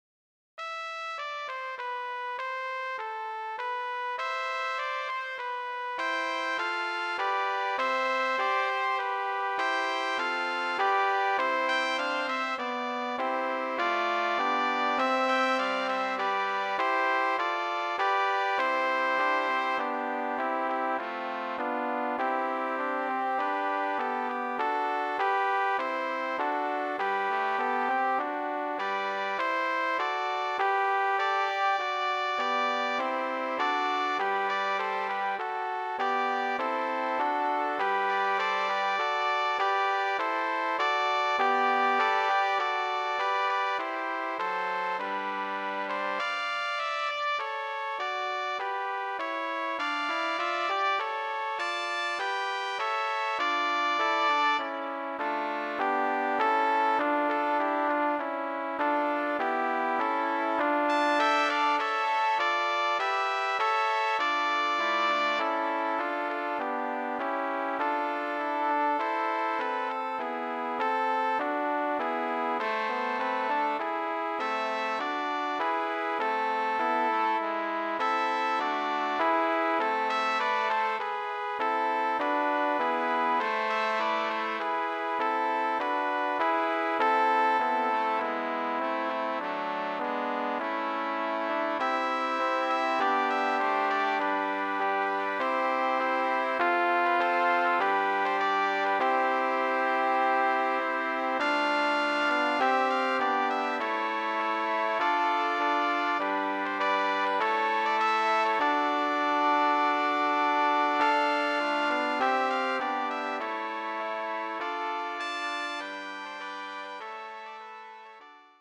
Voicing: Trumpet Ensemble